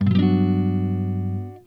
LONGJAZZ 1.wav